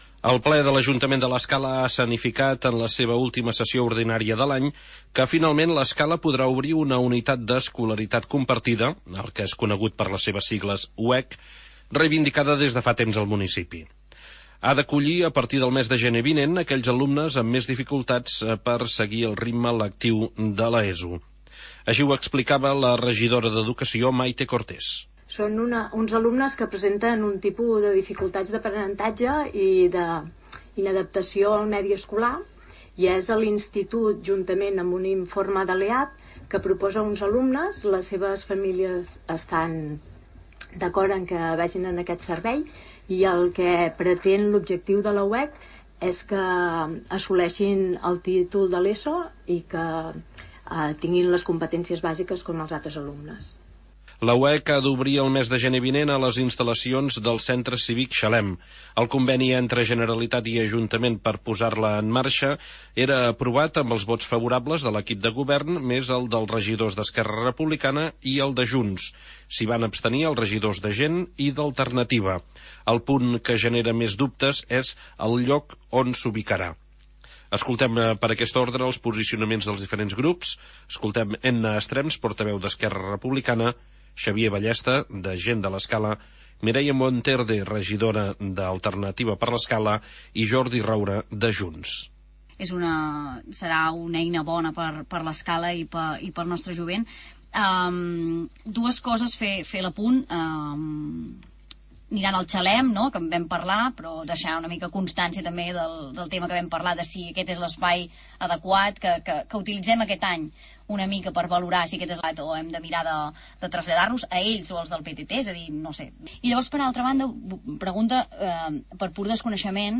El consum energètic dels llums de Nadal, l'aigua potable mal aprofitada, la retolació en català, la calefacció de Ballmanetes o el dol perinatal, qüestions plantejades per l'oposició a l'apartat de precs i preguntes del ple. Fem a l'informatiu d'avui un recull dels fragments més destacats d'aquest apartat.